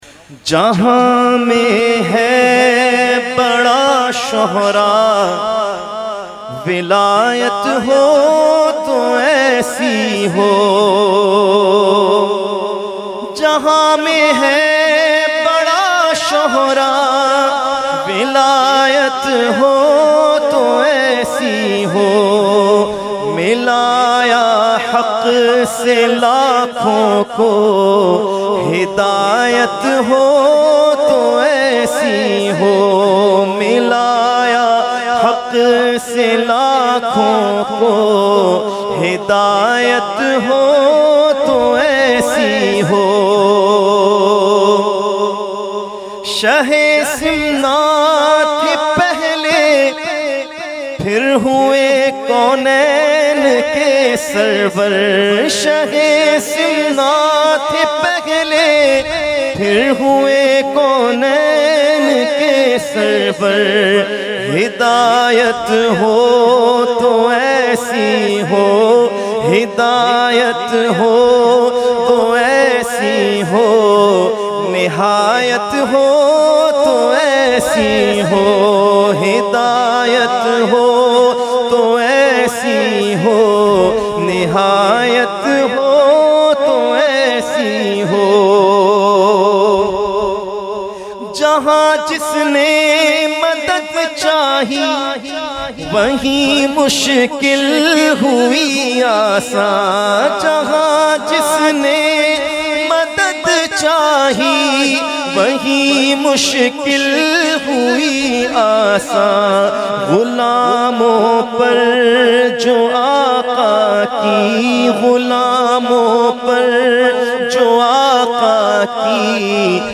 Category : Manqabat | Language : UrduEvent : Urs Makhdoome Samnani 2020